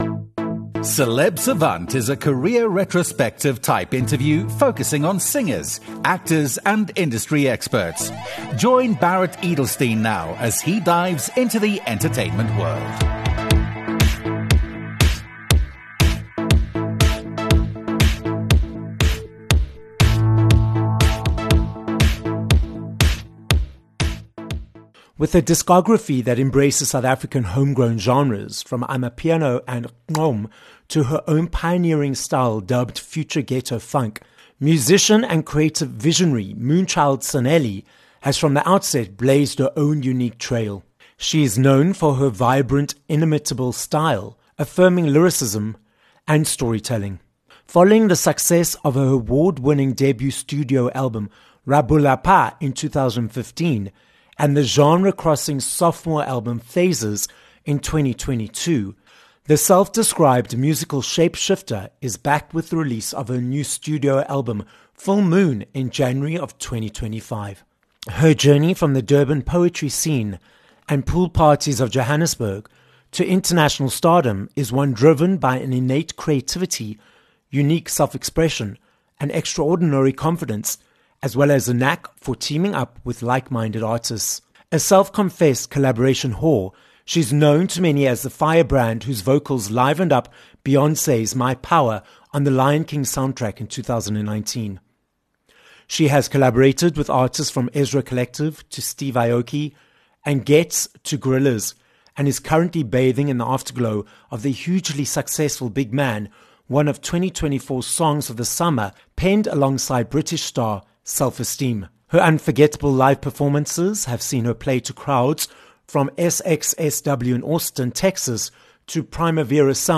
Moonchild Sanelly - a South African singer, songwriter, and fashion designer - joins us on this episode of Celeb Savant.